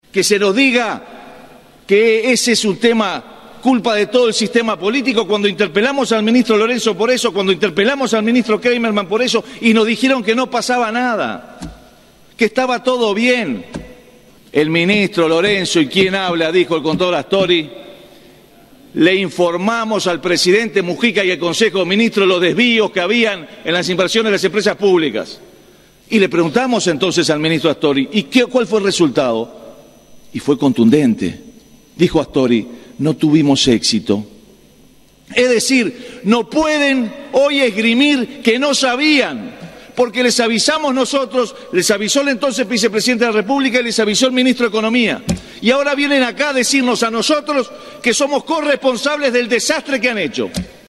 Interpelación en el Senado